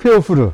津軽方言：古代の発音
しかし、津軽方言では、ハ行から始まる言葉は、p- とは h- の中間音である f-　として残っていることが確認できます！